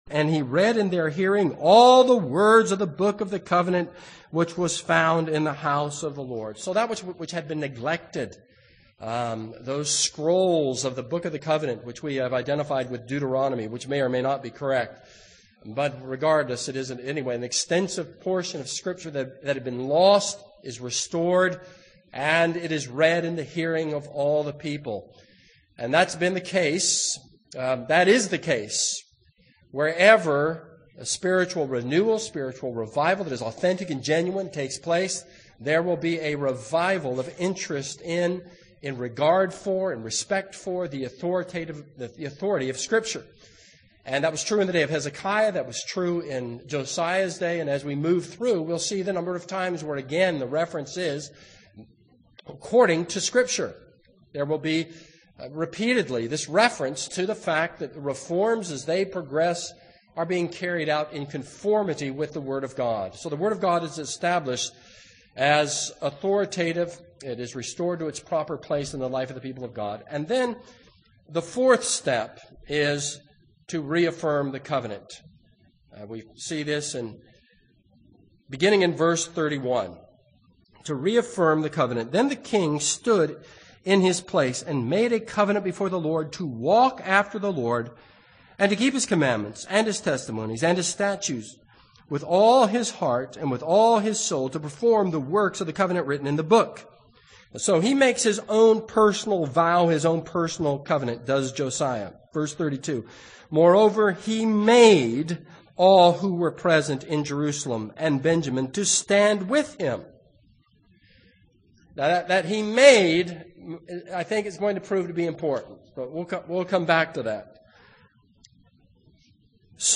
This is a sermon on 2 Kings 22-23:30.